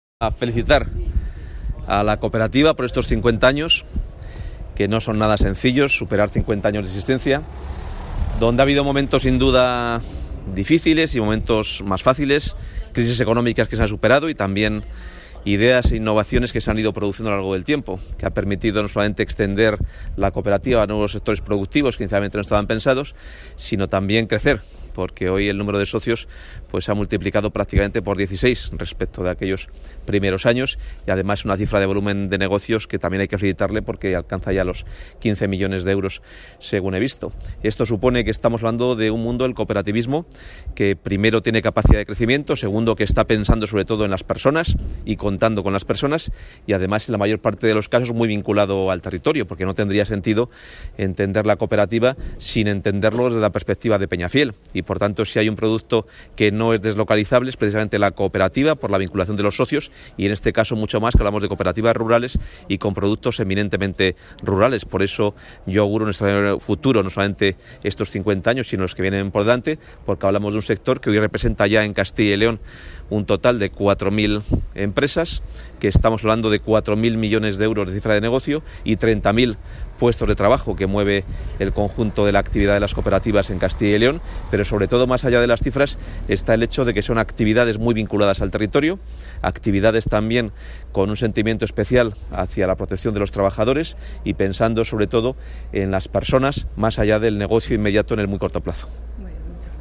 El consejero de Empleo, Carlos Fernández Carriedo, ha clausurado en Peñafiel (Valladolid) los actos conmemorativos del 50 aniversario...
Consejero de Empleo.